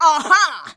AHA.wav